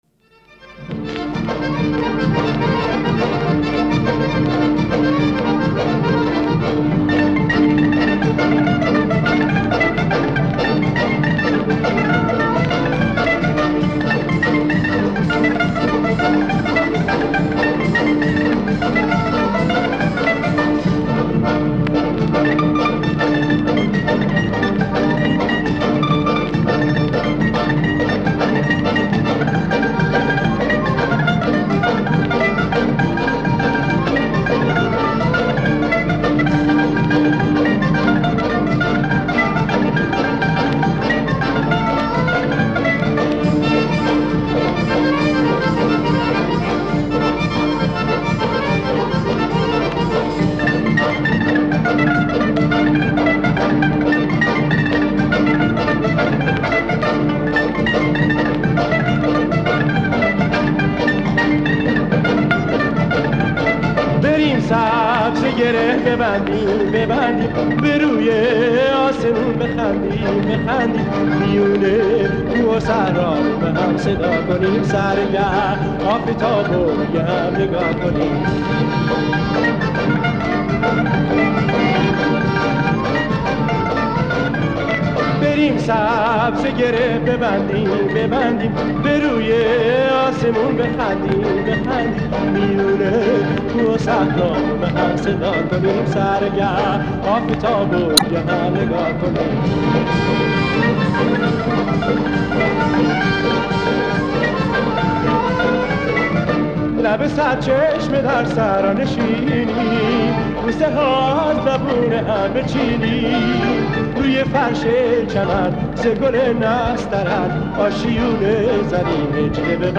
با ملودی شاد و پرانرژی